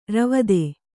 ♪ ravade